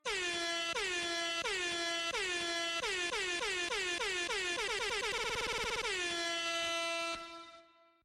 Annoying Air horn 2